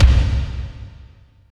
35.09 KICK.wav